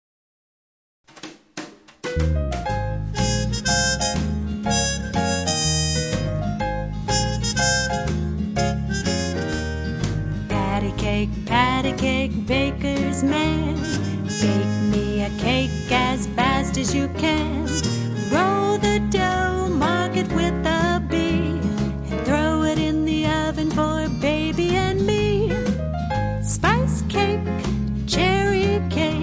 Nursery Rhyme